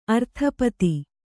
♪ arthapati